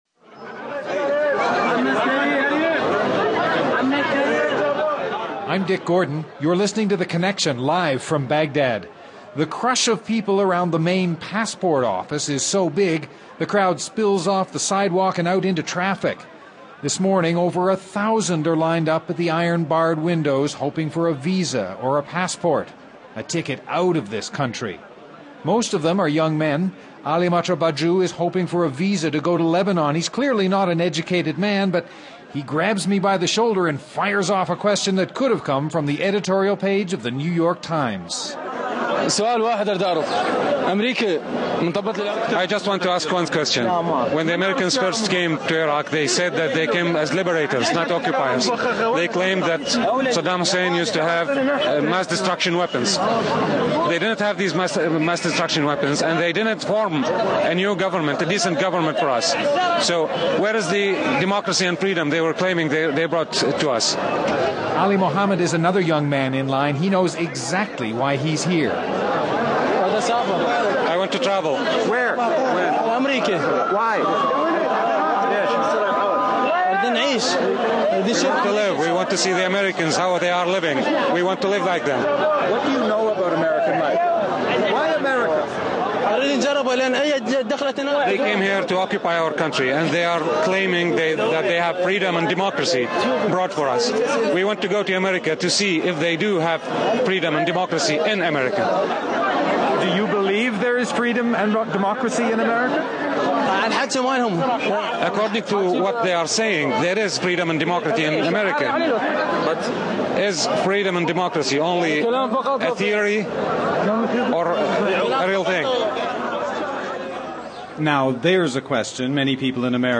university students in Iraq.